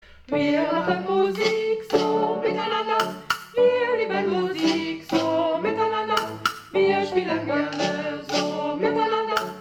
mit Instrumenten